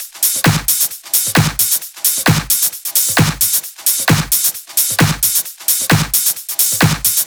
VFH3 132BPM Elemental Kit 5.wav